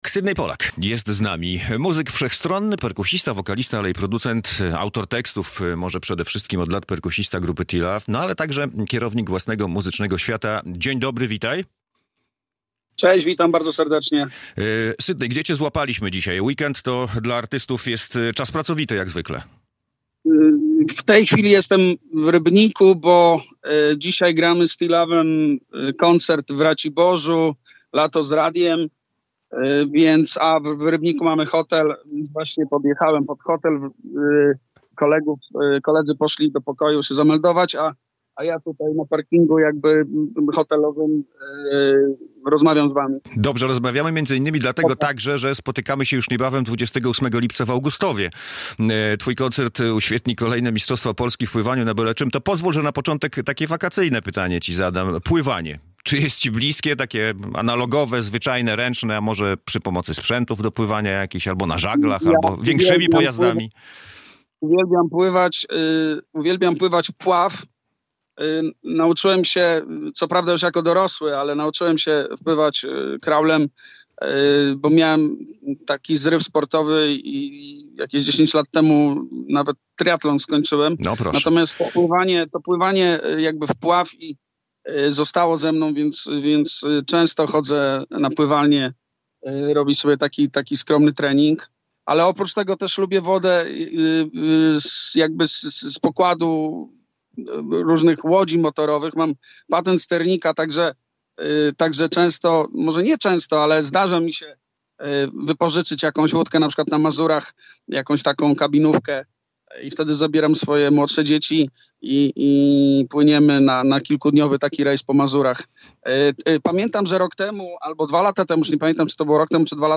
Radio Białystok | Gość | Sidney Polak - polski wokalista, perkusista, autor tekstów, muzyk i kompozytor